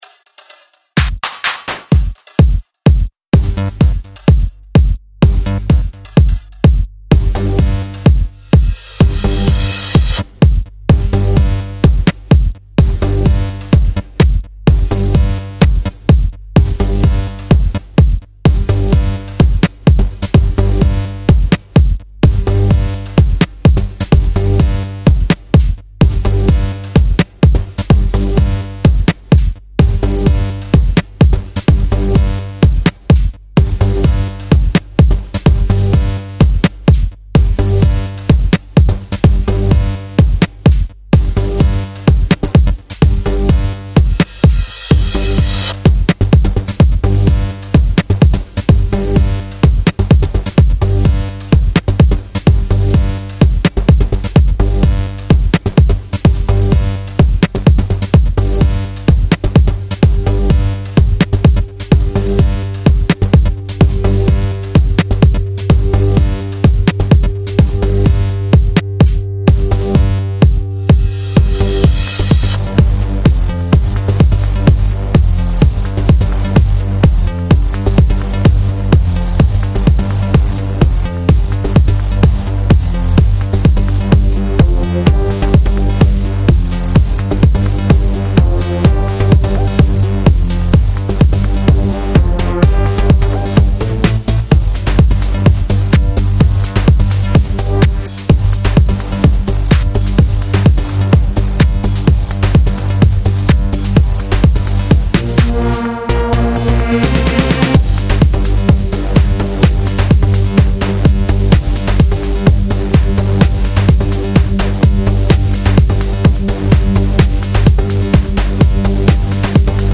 Kick un peu fort à mon gout mais sinon on s'ennuie pas, de bons effets, bonne maitrise (un ptit detune sympa notamment), bons jeux d'enveloppes sur la bass (on sent l'arrivée de vsti puissants dans ta liste de vsti )...Bien joué!